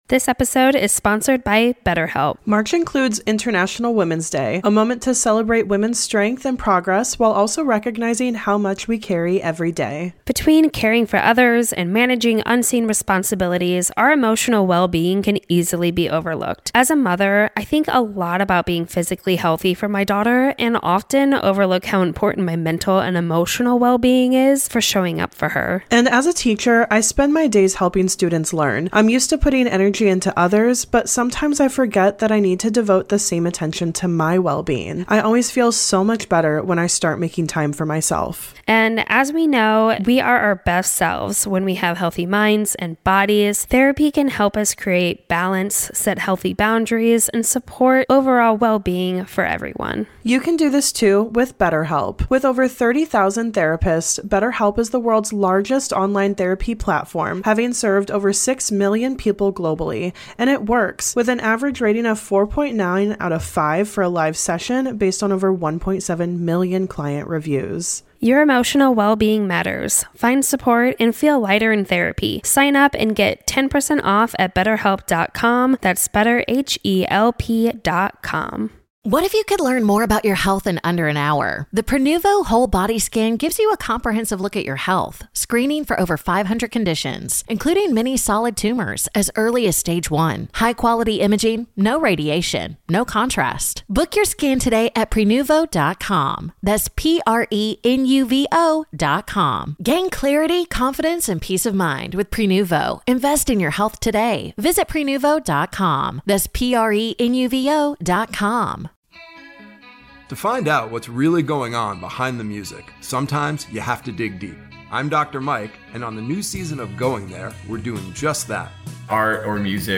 How did they become friends, gain interest in BTS, and what’s their favorite BTS content? The answers to these questions and more can be heard on this week’s listener requested Q&A episode!